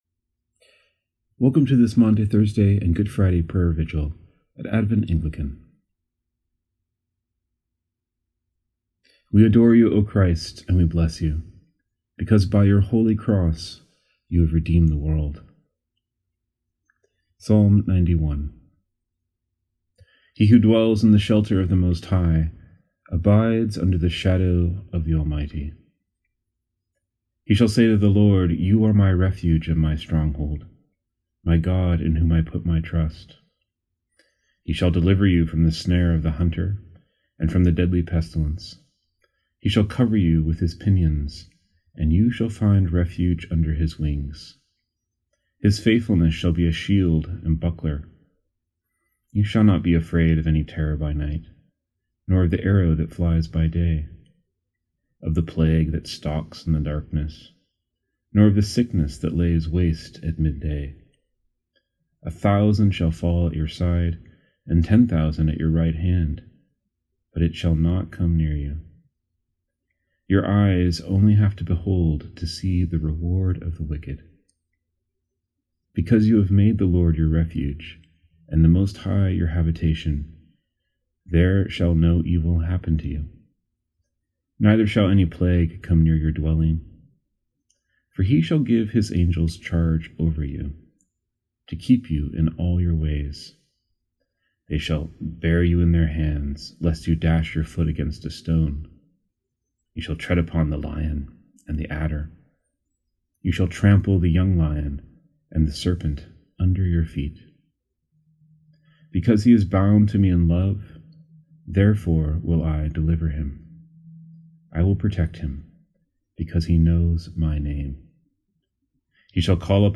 Liturgy for Maundy Thursday / Good Friday
You can follow along using a PDF of the liturgy here or simply listen as I read selected psalms, collects, and Scripture passages that testify to the passion of our Lord.